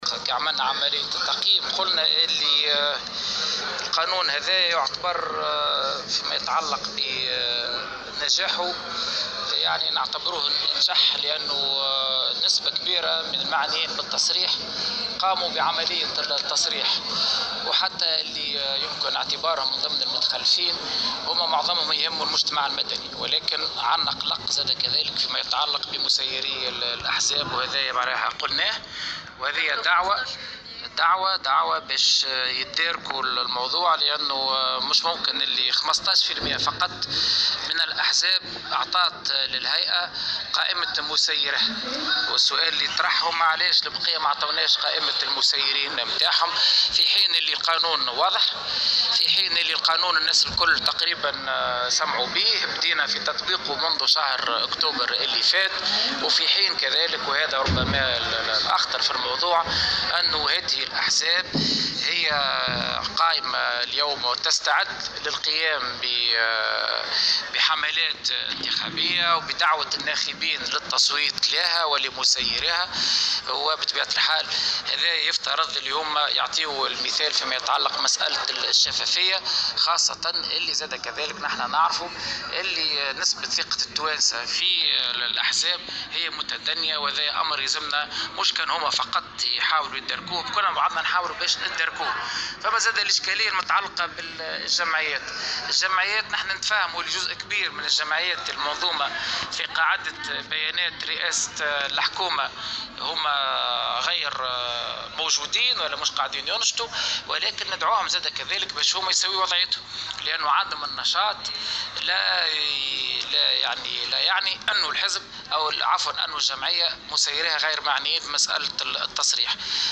وأضاف الطبيب في تصريح اليوم لمراسلة "الجوهرة أف أم" على هامش ورشة عمل نظمتها الهيئة بعنوان " التصريح بالمكاسب والمصالح وتكوين قاعدة البيانات" أن 15 % فقط من رؤساء الاحزاب السياسية ومسيّريها استجابوا للقانون وصرّحوا بمكاسبهم، على الرغم من وجود تفاعل كبير على مستوى الهياكل العمومية، وفق قوله.